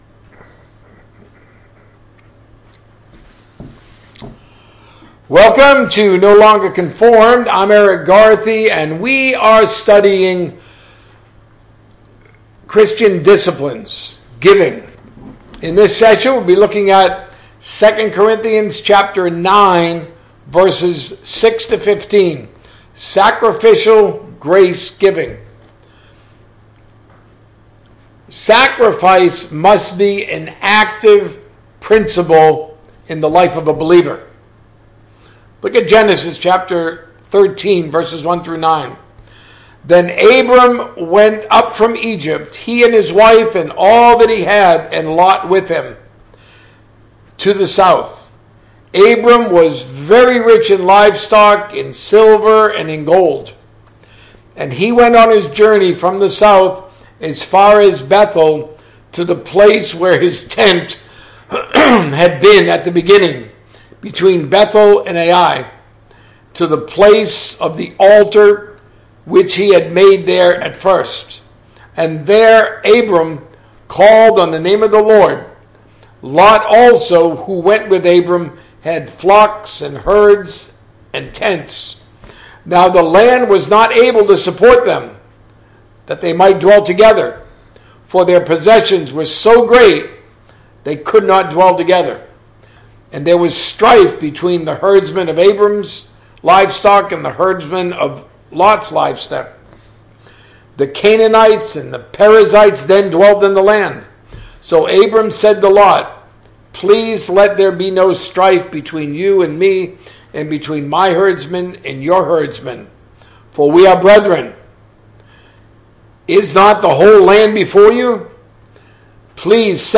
A message from the series "Christians Disciplines."